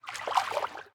latest / assets / minecraft / sounds / mob / dolphin / swim3.ogg
swim3.ogg